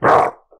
spawners_mobs_mummy_attack.1.ogg